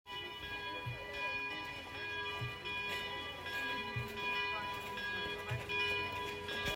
나무 벤치에 앉아 설경을 바라보는 나. 어디선가 종소리가 들려온다. 아이폰의 녹음버튼을 눌러 종소리를 담아보자, 겨울바람이 아름다운 종소리와 섞여 불어온다.
뮈렌 종소리.m4a